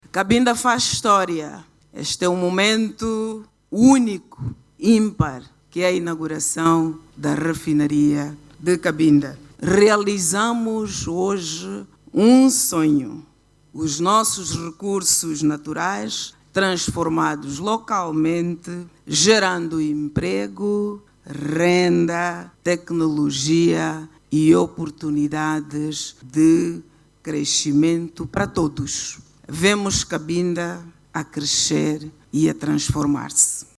O Presidente da República, João Lourenço, inaugurou esta manhã a Refinaria de Cabinda com uma capacidade de produção de 60 mil barris por dia após a conclusão da segunda fase. A governadora de Cabinda, Suzana D´Abreu, disse que a província faz história com a inauguração da refinaria, que se traduz na transformação dos recursos naturais que vão gerar emprego, renda e tecnologia.